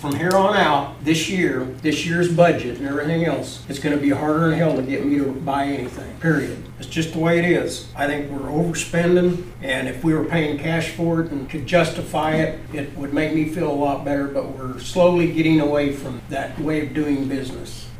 This comes in under the $70,000 that was budgeted for the lease-purchase payments, but Commissioner Pat Weixelman was still hesitant in his approval.